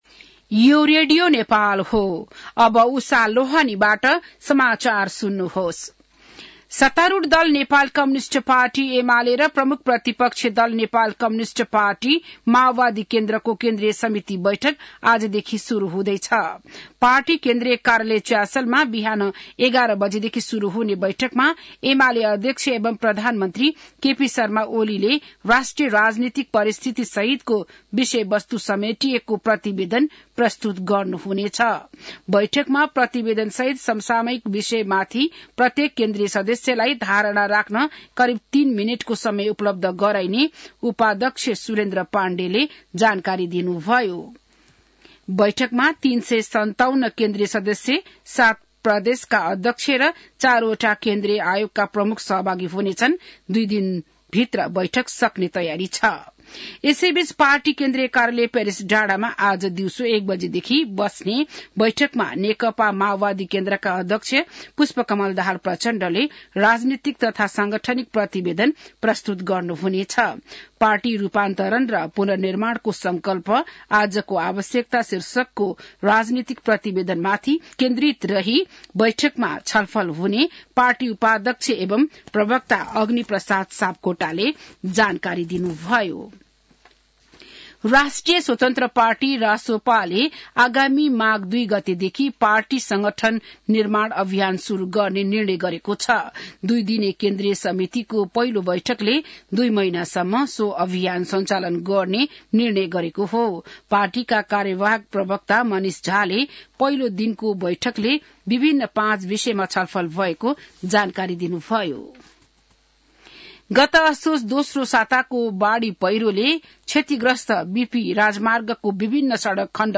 बिहान १० बजेको नेपाली समाचार : २२ पुष , २०८१